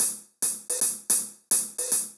Index of /musicradar/ultimate-hihat-samples/110bpm
UHH_AcoustiHatA_110-04.wav